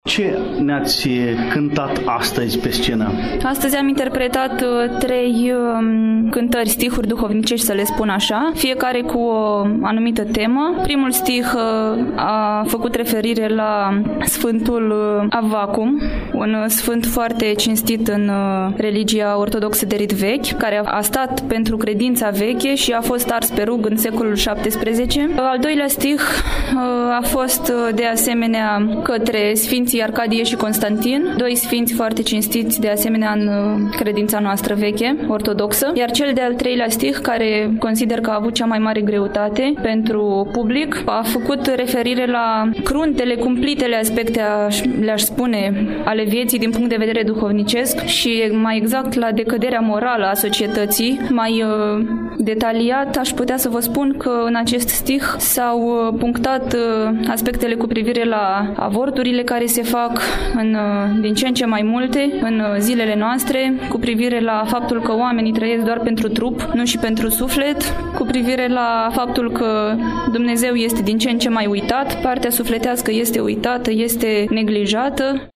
Astăzi relatăm de la FESTIVALUL-CONCURS NAȚIONAL ȘCOLAR DE INTERPRETARE DE NOTE PSALTICE ȘI STIHURI DUHOVNICEȘTI „IOACHIM IVANOV”, ediția a VI-a, de la Galați, eveniment desfășurat în perioada 6-9 august, în incinta Teatrului Dramatic „Fani Tardini”, de pe strada Domnească, Numărul 59.
un interviu